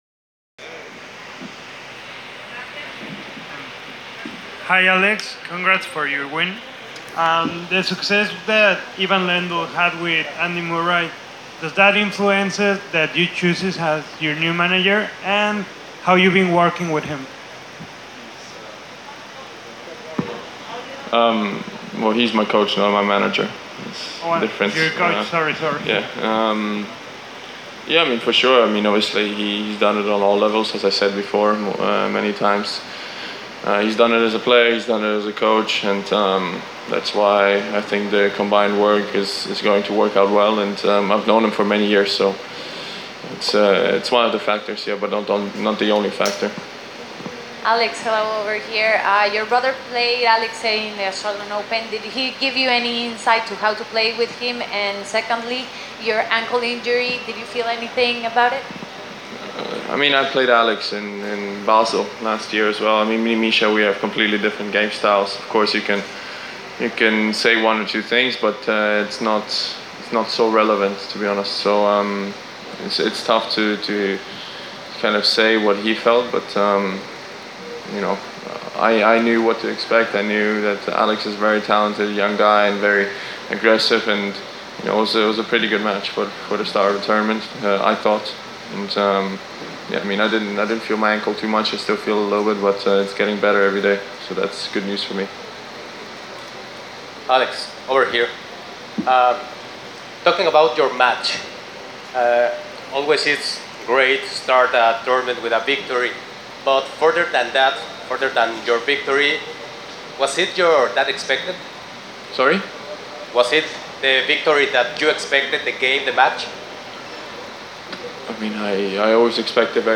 Press Conference – Alexander Zverev (26/02/2019)